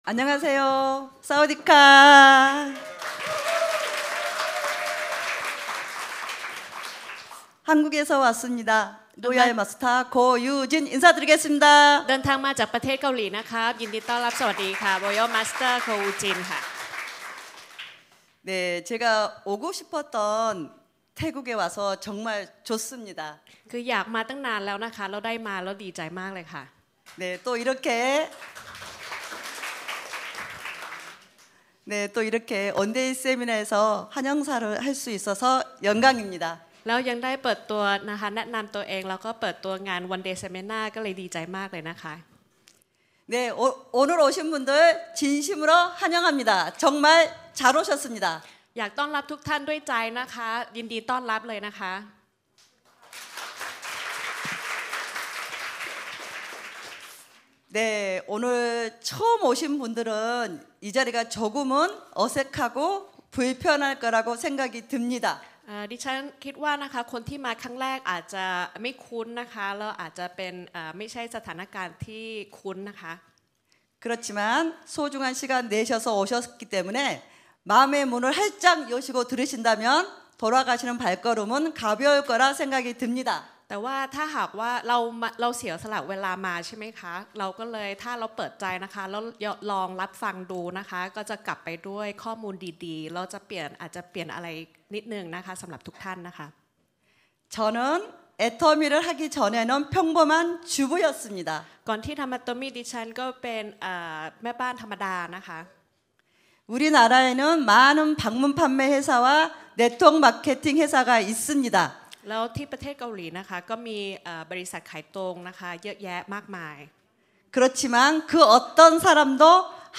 กล่าวต้อนรับ